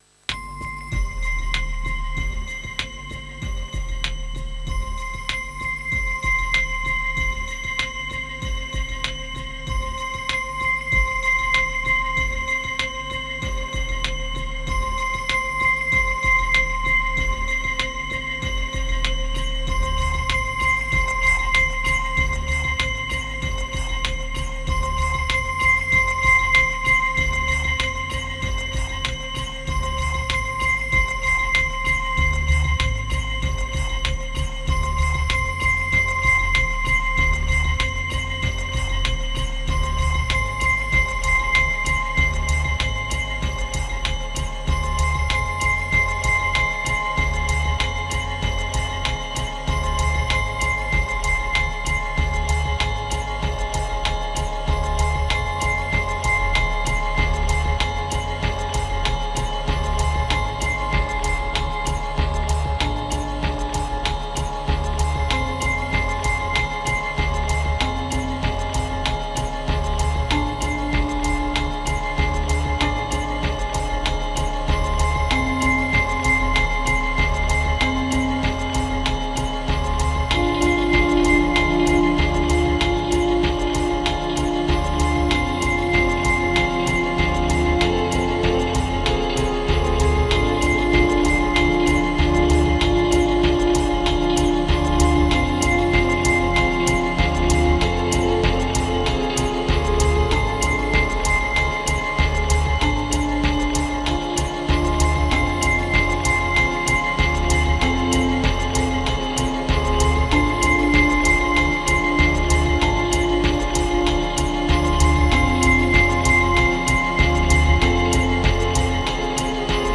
1741📈 - 71%🤔 - 48BPM🔊 - 2023-02-11📅 - 363🌟